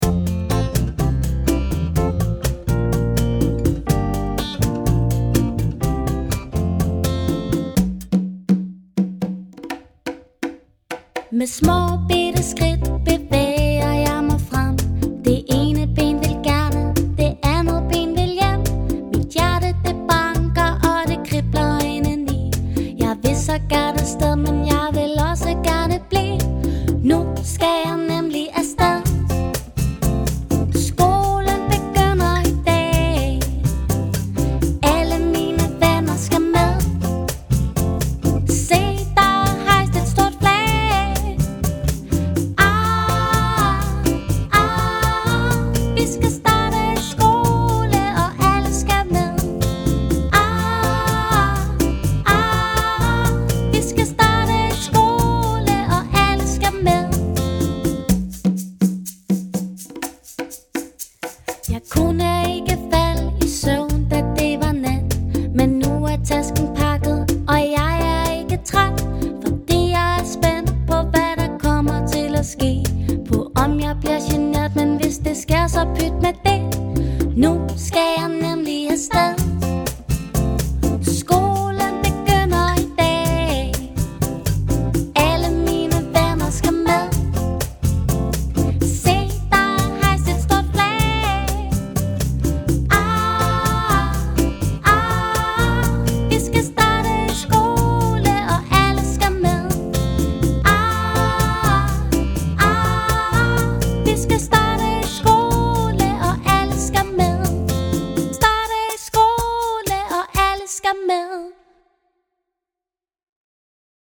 indspillet i en foreløbig demo-version